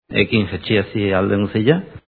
In Etxalar hek exists for haiek: